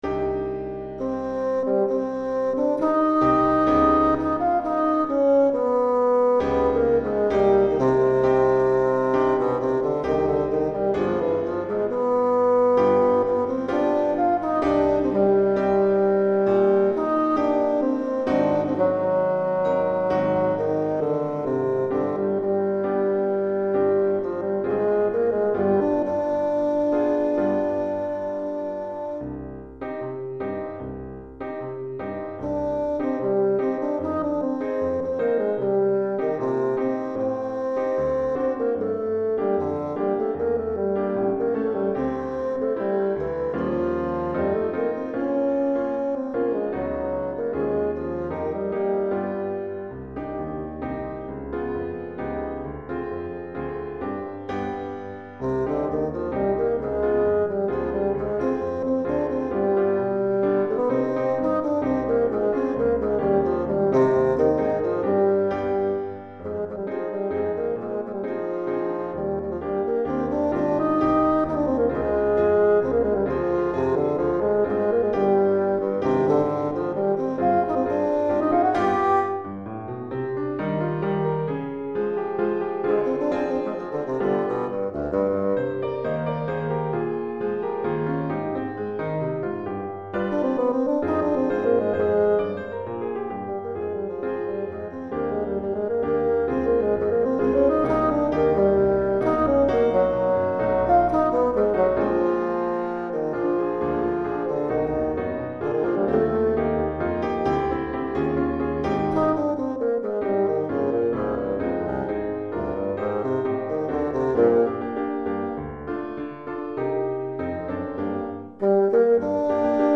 Basson et Piano